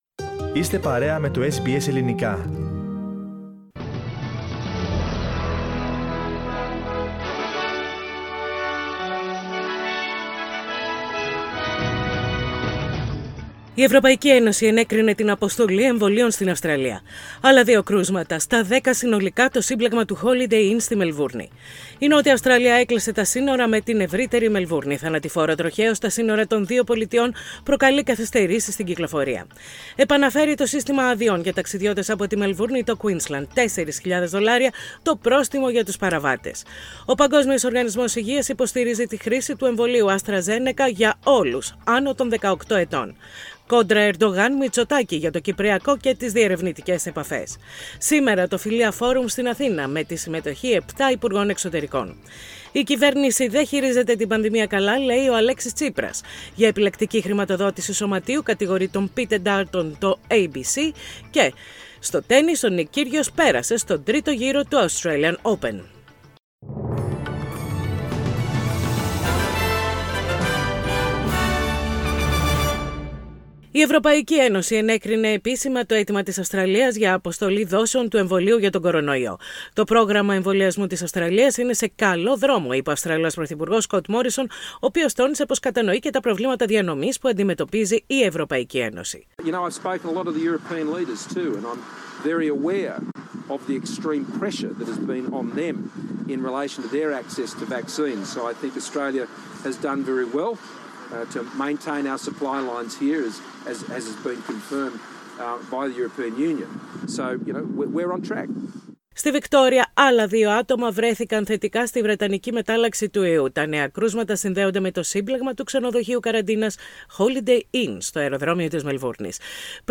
Δελτίο ειδήσεων - Πέμπτη 11.2.21